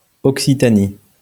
L'Occitania[1] (franzëus: Occitanie [ɔksitani] (Nfurmazions sun chësta usc